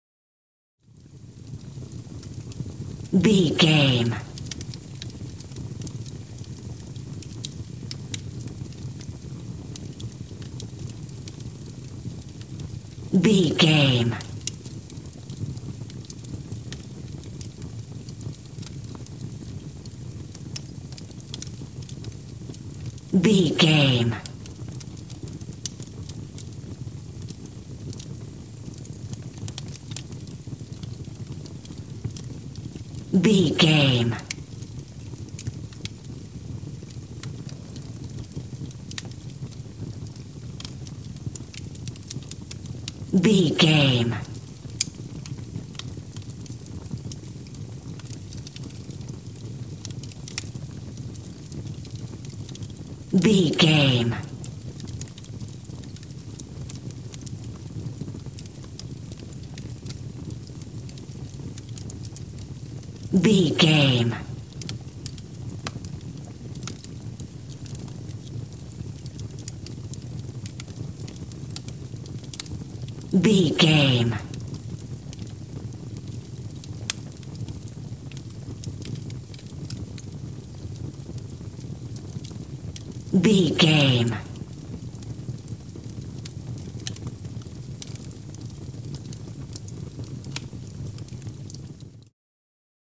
Fire with crakle
Sound Effects
torch
fireplace